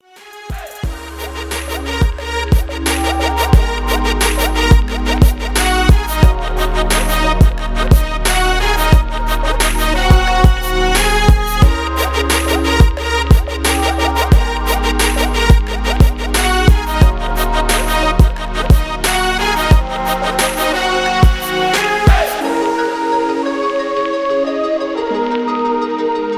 Elektronisk musik